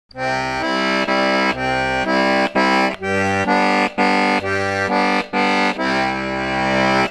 For a waltz, the left hand pattern is:  root-chord-chord-root-chord-chord
This is the classic one, two, three, one, two, three, or, “Oom-Pah-Pah Oom-Pah-Pah”.
Waltz Bass Chords